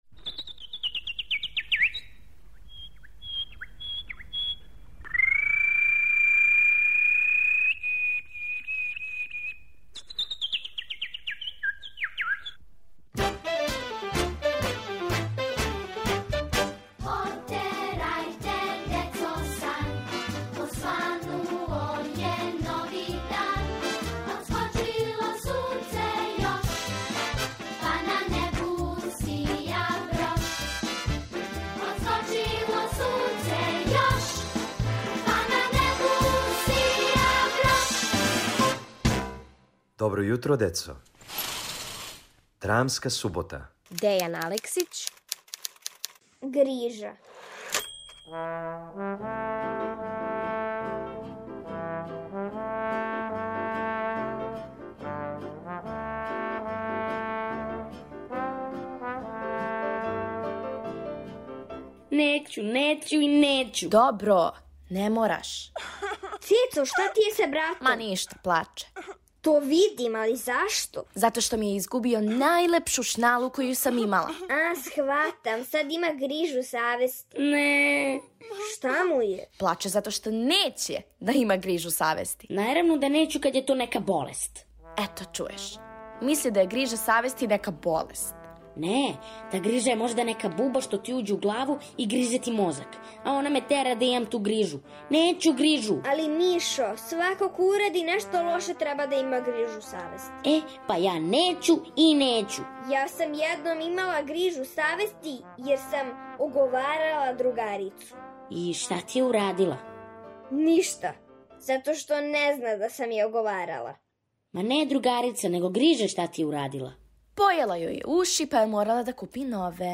У овој краткој драми Дејана Алексића сазнајте шта је грижа, да ли је то нека буба и како она гризе...